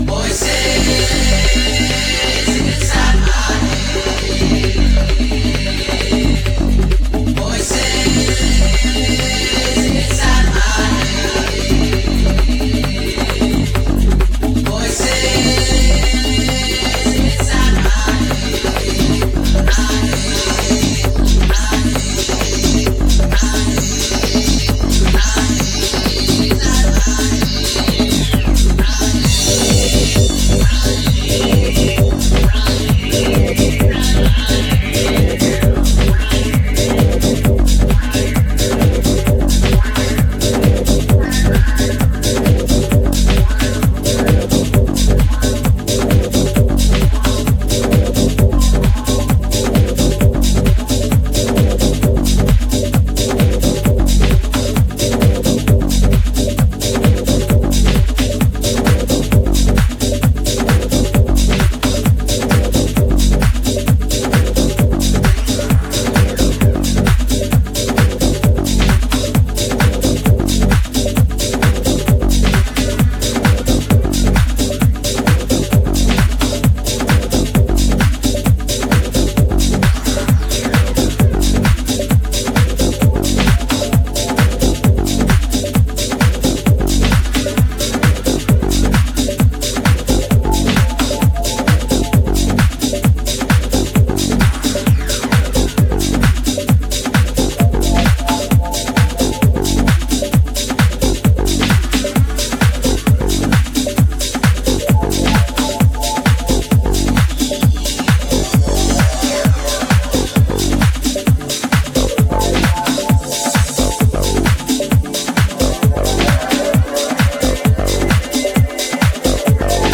house, deep-trance és tribal kaptak helyett